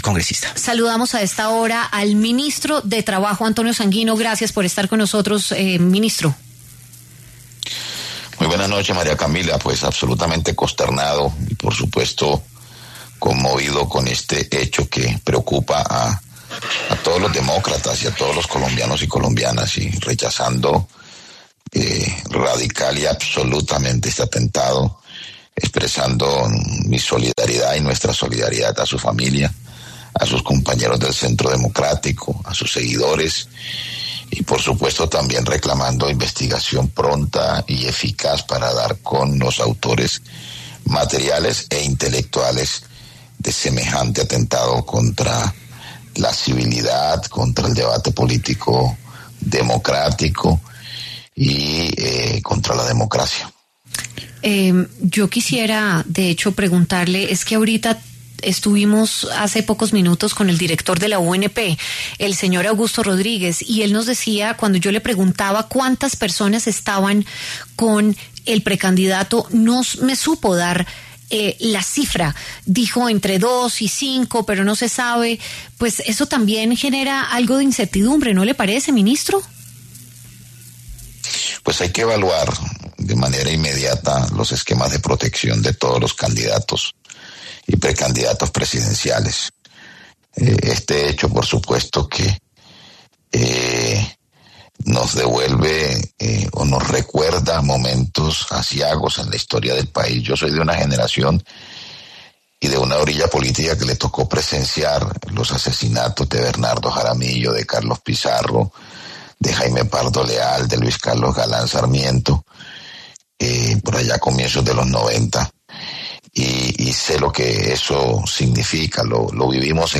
Antonio Sanguino, ministro del Trabajo, habló en W Radio a propósito del atentado en contra del precandidato presidencial Miguel Uribe.